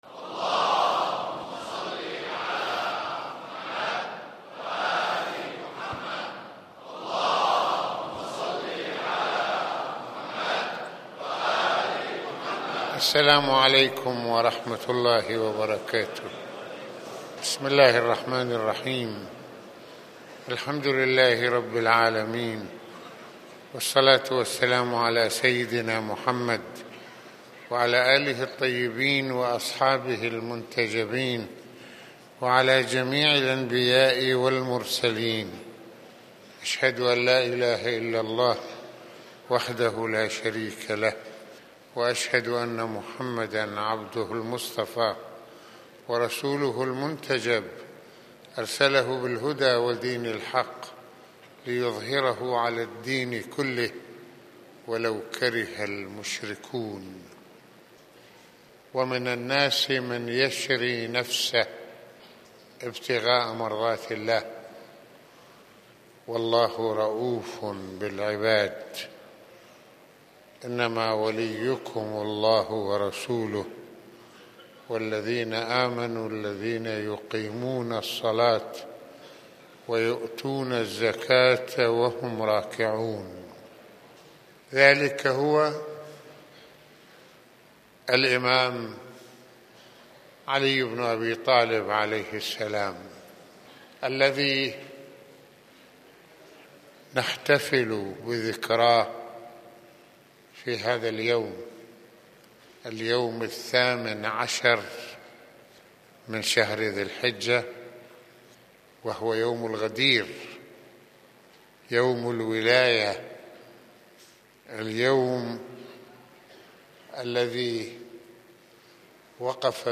- المناسبة : خطبة الجمعة المكان : مسجد الإمامين الحسنين (ع) المدة : 29د | 31ث المواضيع : بيعة يوم الغدير - عليٌّ(ع) يتمثل برسول الله(ص) - علي(ع) فارس الإسلام - علي(ع) ومسؤولية الإسلام - الولاية: ارتباط عقلي وروحي.